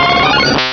pokeemerald / sound / direct_sound_samples / cries / venonat.aif
venonat.aif